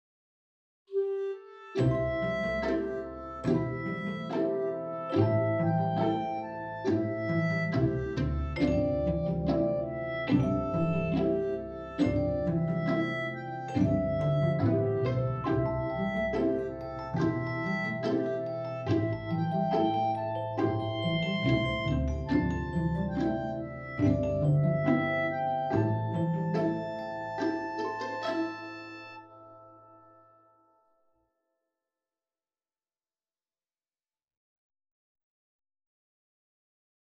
バイノーラル処理ではヘッドホンで3Dオーディオを再生できます。
こちらのクリップでバイノーラル音を聞くことが出来ます(ヘッドホンを使って下さい）。まるで音が頭の周りで回っているかのように感じられますね。
kougen_binural.wav